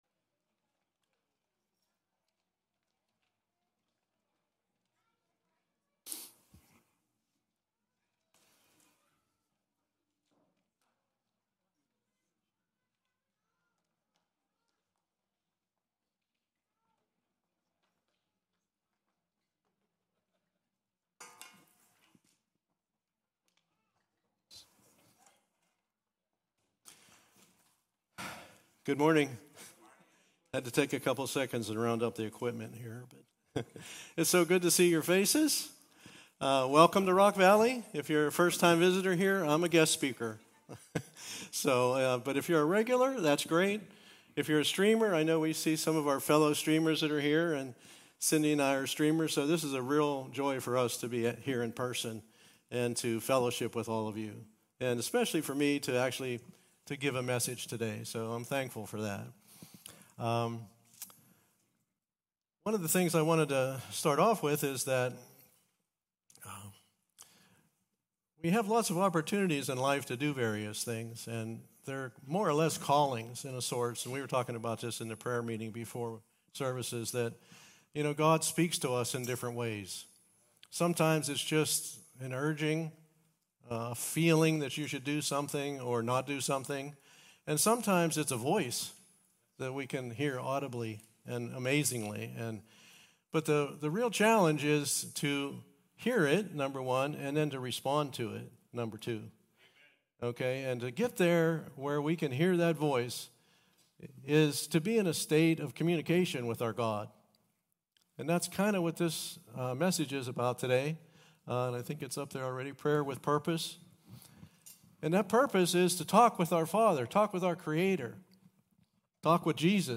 In this teaching, we will explore the scriptures to find guidance regarding what our good Father expects from us when we pray for our own needs AND